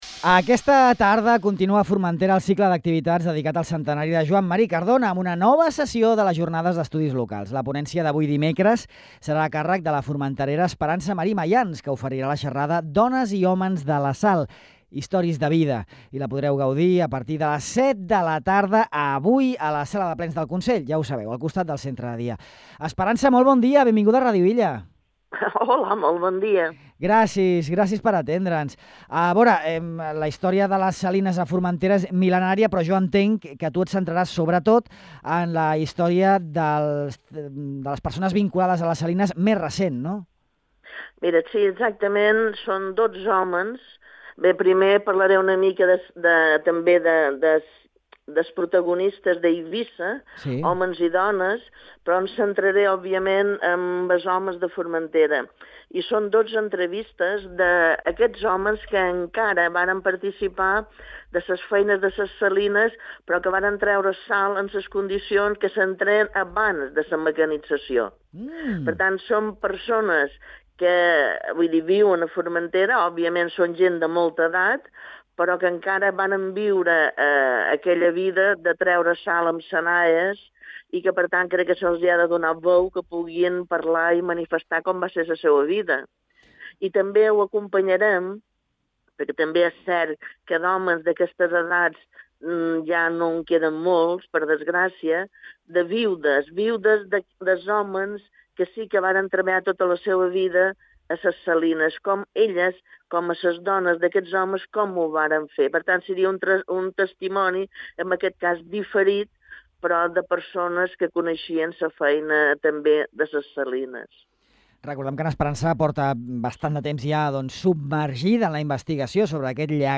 En aquesta entrevista a Ràdio Illa